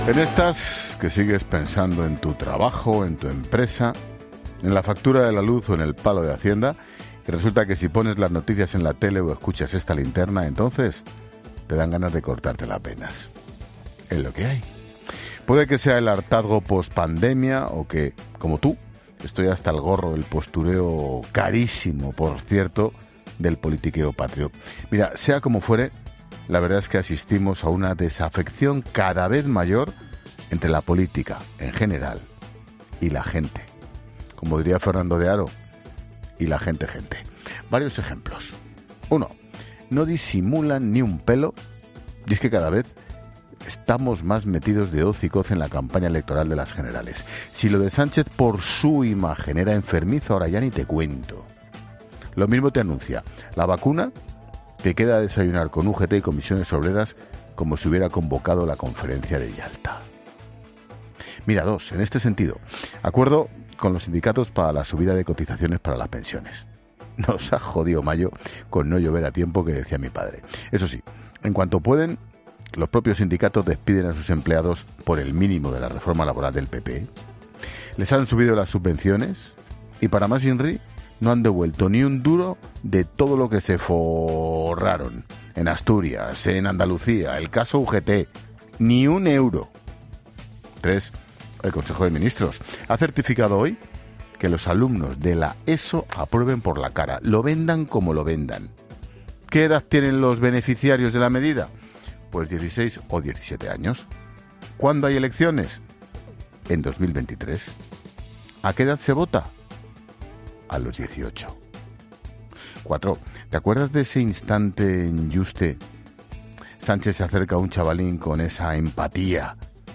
Monólogo de Expósito
El director de 'La Linterna', Ángel Expósito, analiza en su monólogo las principales noticias políticas del día